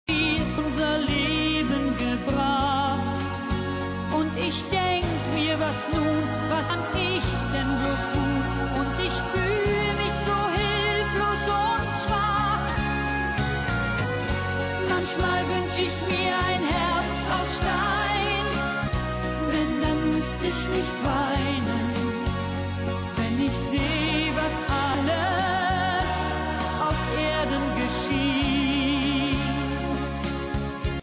Nanu, eine Volksliedschlagersängerin in dieser Auswahl?
Aufnahme mit 5kB/s, fs=16kHz Hörprobe